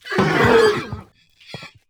combat / creatures / horse